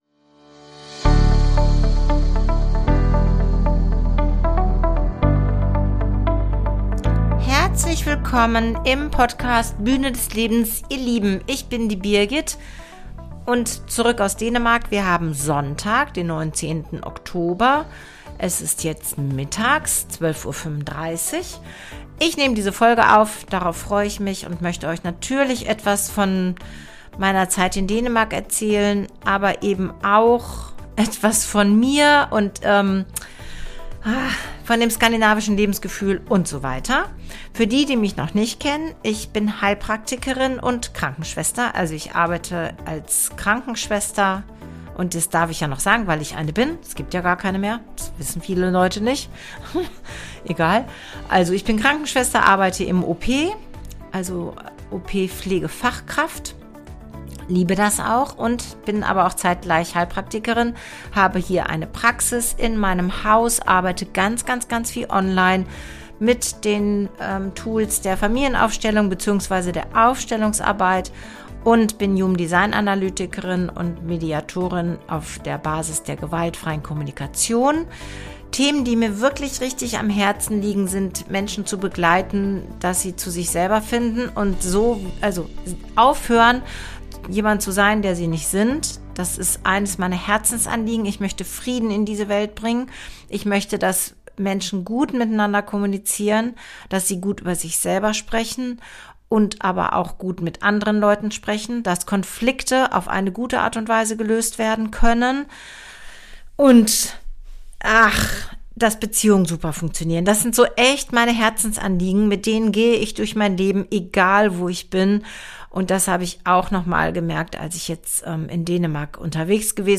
Begleitet wird die Folge vom Song „Wenn das Meer mich ruft“, der die Sehnsucht und die Ruhe des Nordens in Töne fasst.